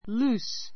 lúːs る ー ス （ ⦣ × る ー ズ ではない）